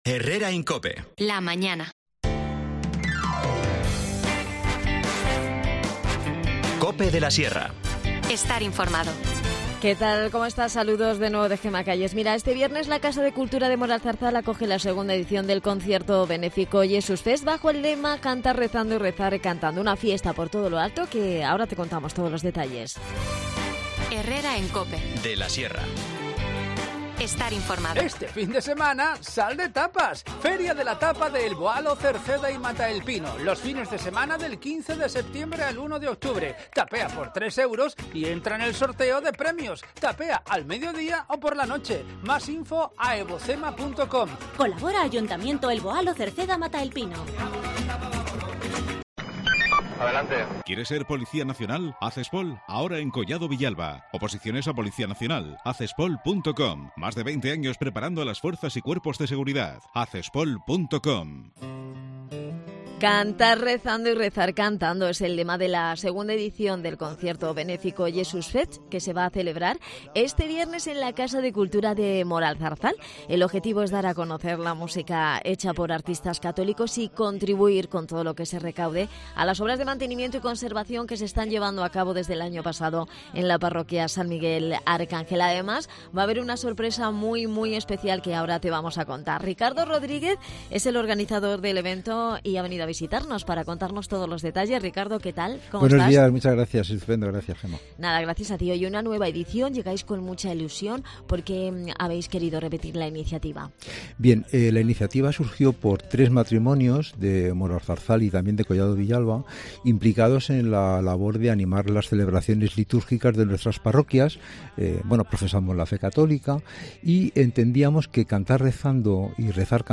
Te contamos las últimas noticias de la Sierra de Guadarrama con los mejores reportajes y los que más te interesan y las mejores entrevistas, siempre pensando en el oyente.